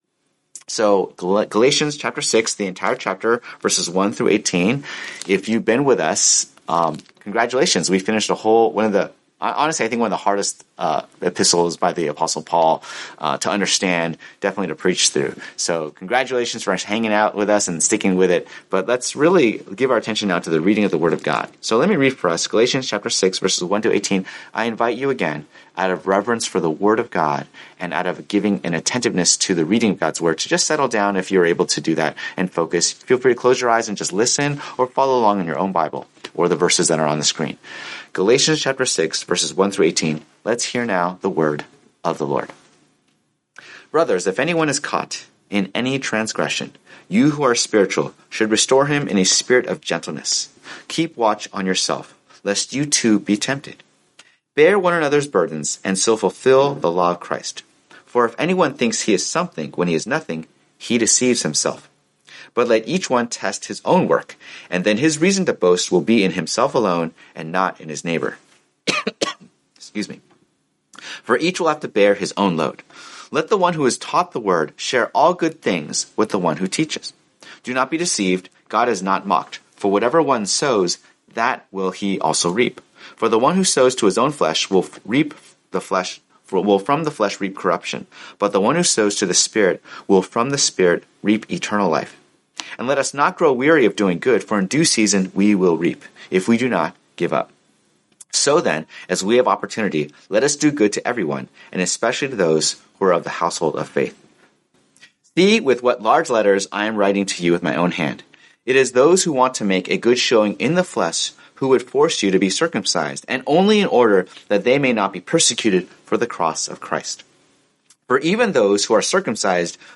Sermons | Boston Chinese Evangelical Church